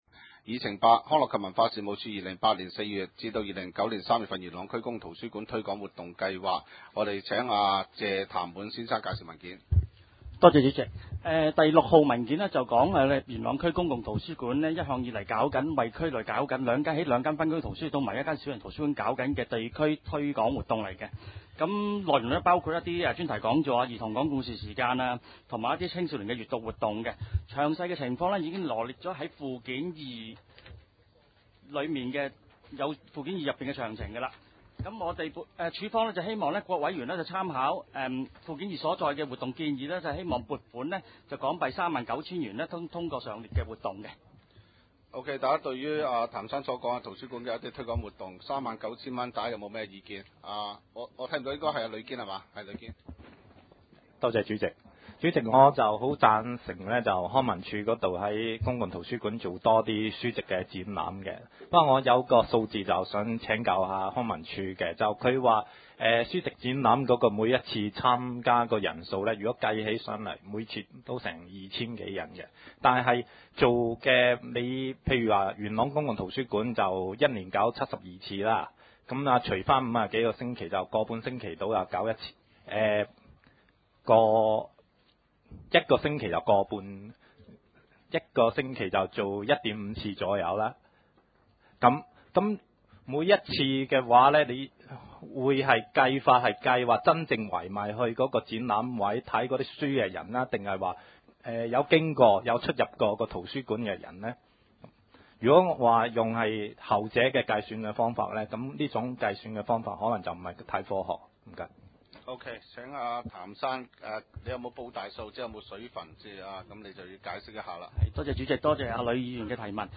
點： 元朗區議會會議廳